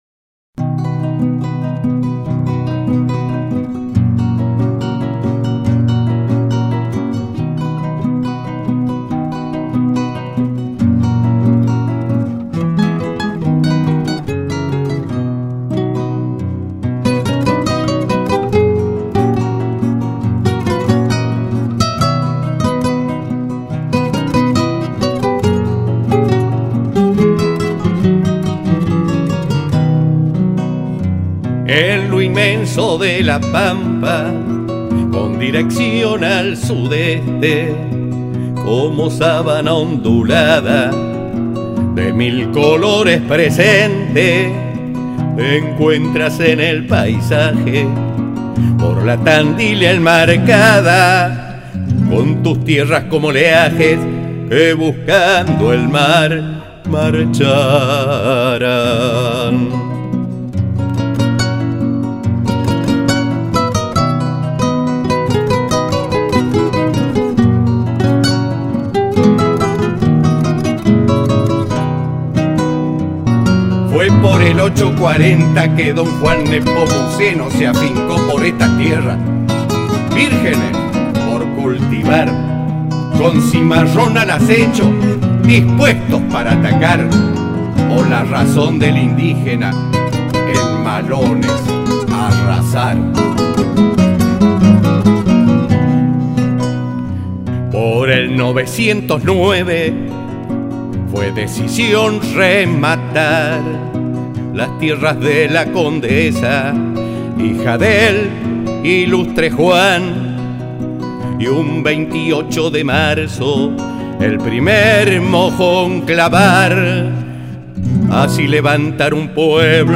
MILONGA "A LOS 100 AÑOS"
en guitarra.